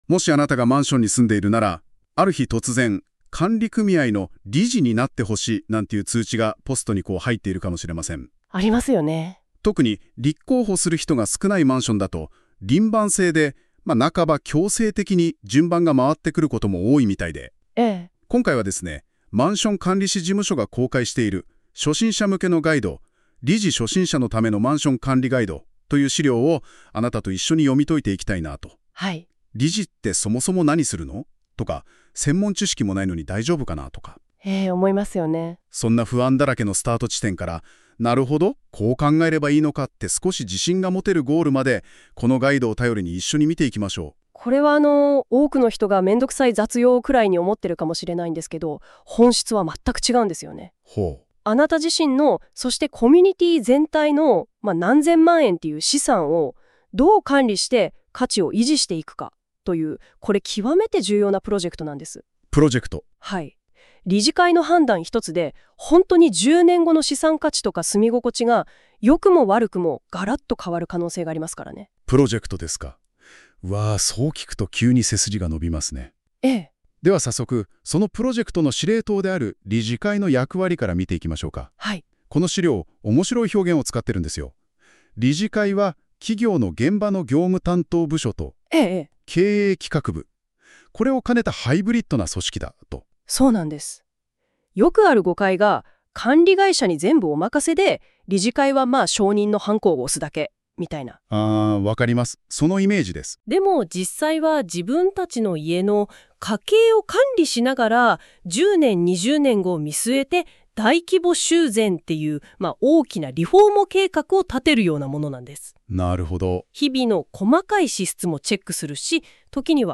🎧 音声解説（約15分） 👂 耳で理解したい方は、対話式の音声解説をこちらで再生できます。